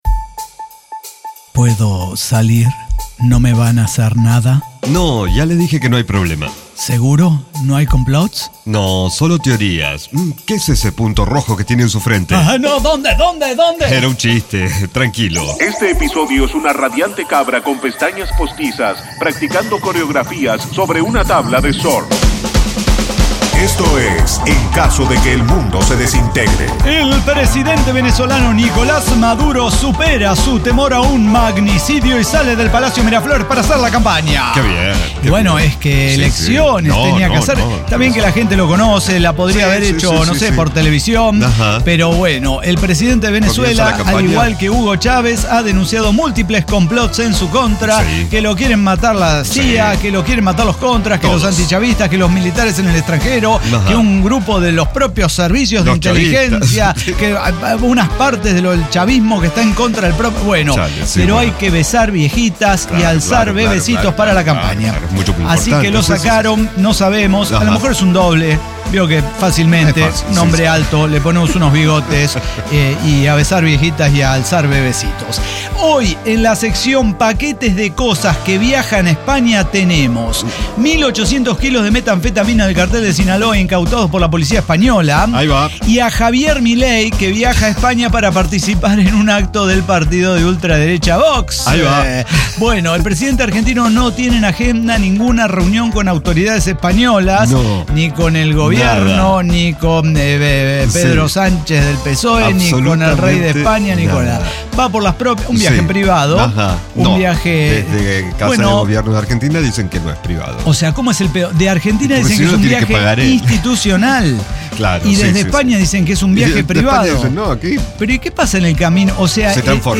ECDQEMSD podcast El Cyber Talk Show – episodio 5785 Pro Y Contra Del Botox